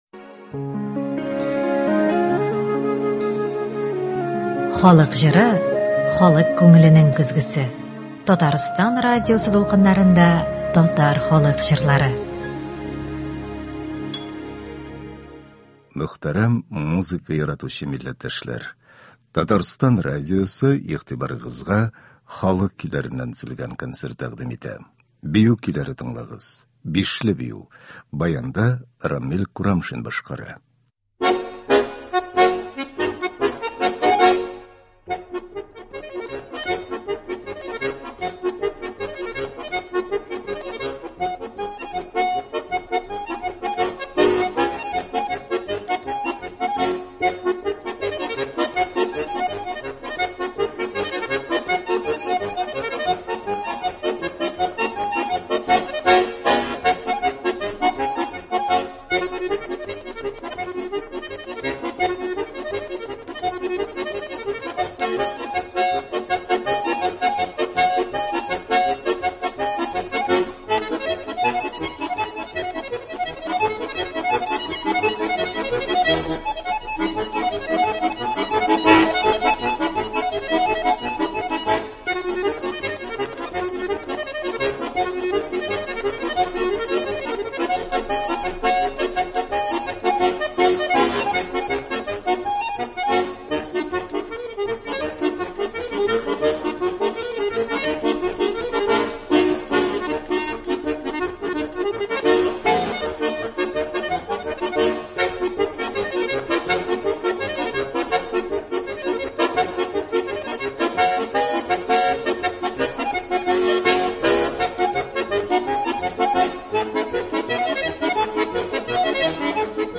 Татар халык көйләре (17.12.22)